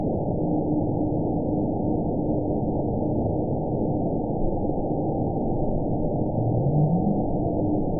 event 912849 date 04/03/22 time 14:12:19 GMT (3 years, 1 month ago) score 9.53 location TSS-AB02 detected by nrw target species NRW annotations +NRW Spectrogram: Frequency (kHz) vs. Time (s) audio not available .wav